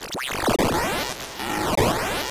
Glitch FX 34.wav